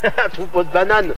Play, download and share Troupeau de bananes original sound button!!!!
haha-troupeau-de-bananes.mp3